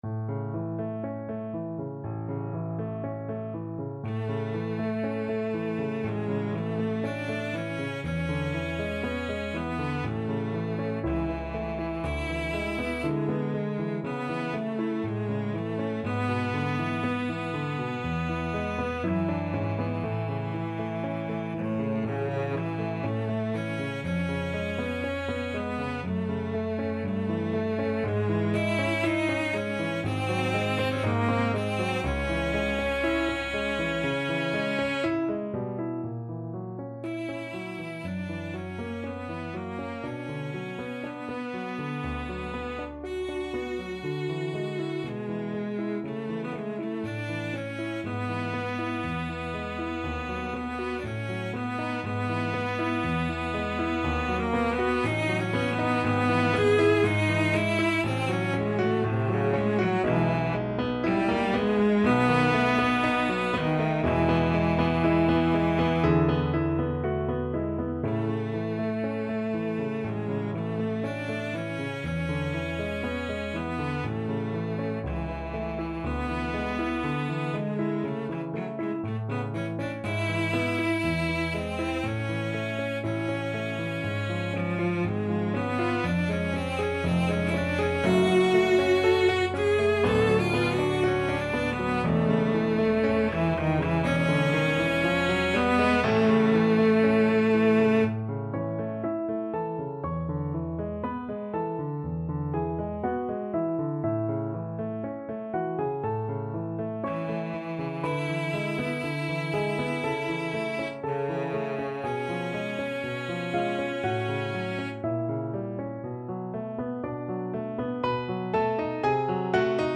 ~ = 100 Andante quasi Adagio
4/4 (View more 4/4 Music)
Classical (View more Classical Cello Music)